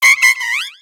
Cri de Nanméouïe dans Pokémon X et Y.